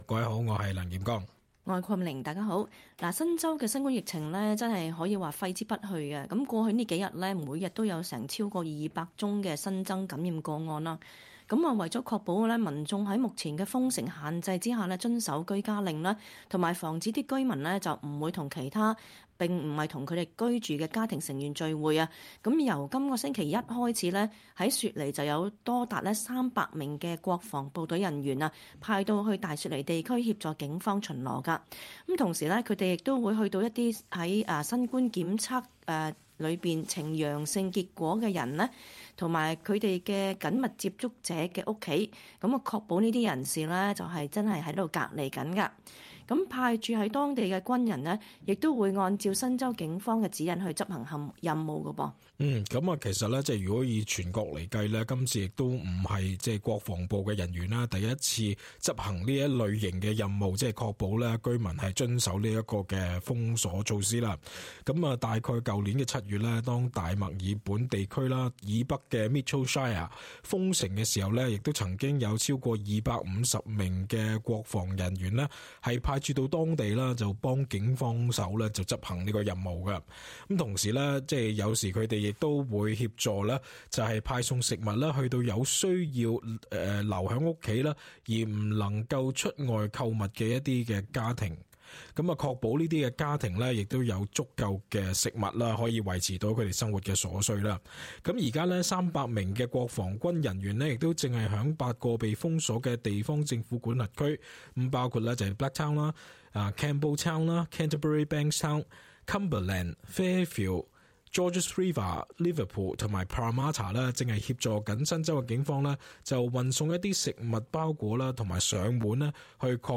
cantonese_-_talkback_-_aug_5_-_final.mp3